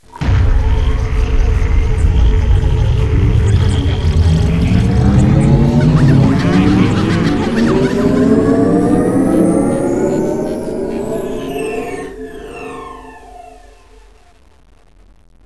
Reduce most sounds to 8bit, up their frequency
power.wav